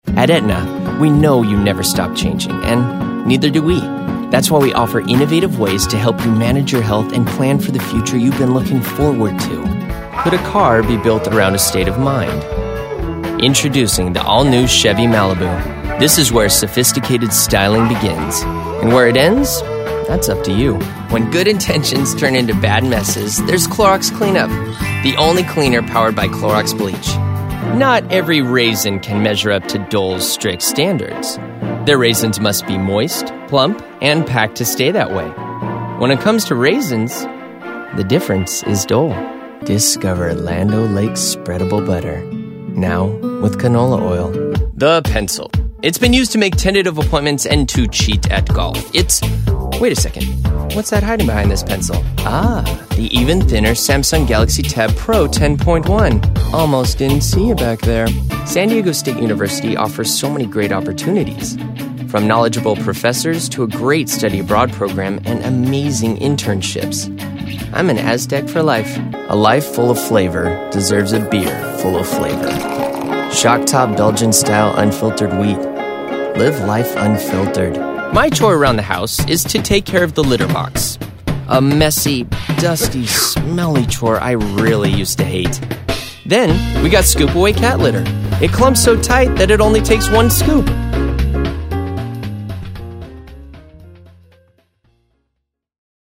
Commercial Demo Reel
Young Adult
Middle Aged